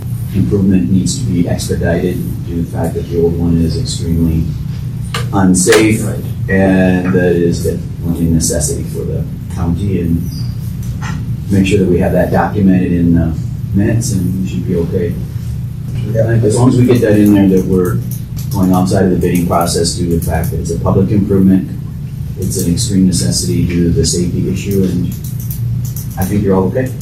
The commission decided to go outside the bidding process to make an emergency purchase from Webster Scale.  Walworth County State’s Attorney Jamie Hare explained that the condition of the current scale has become a safety issue.